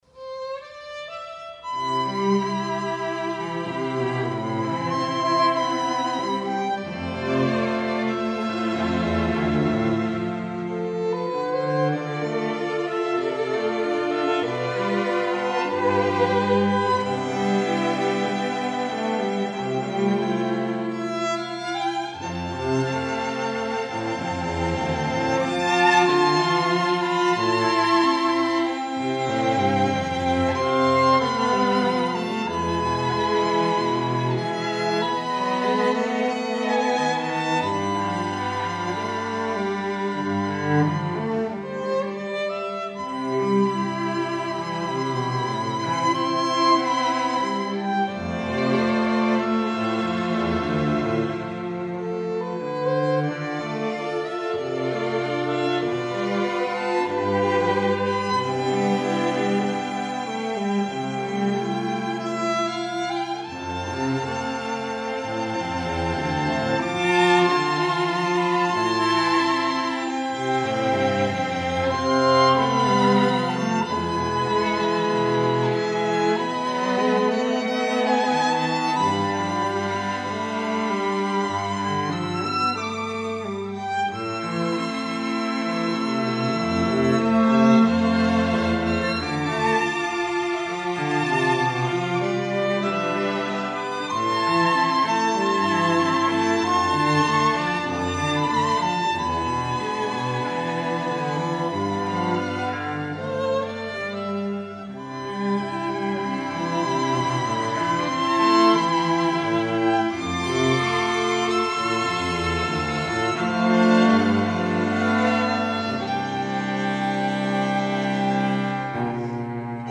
Strings
Each player of this string quartet brings a diversity of experience, from studies with world renown teachers, to performances with great orchestras.